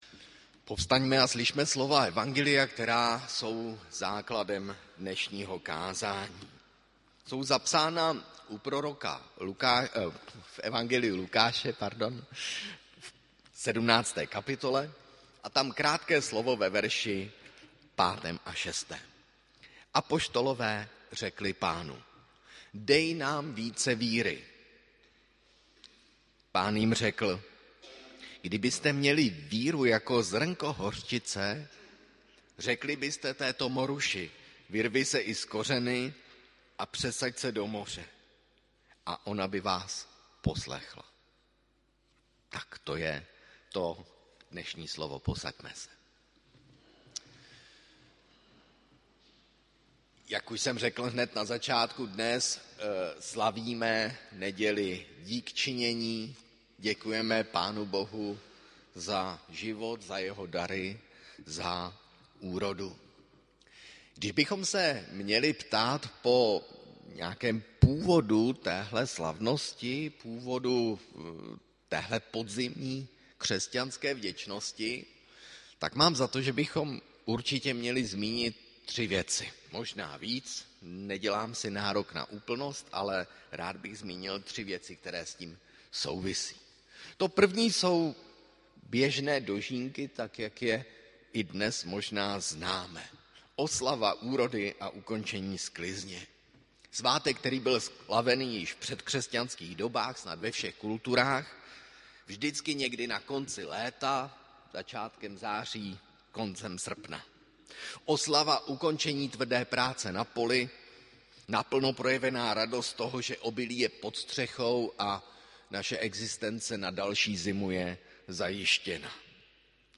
audio kázání
Díkčinění – bohoslužby se sv. Večeří Páně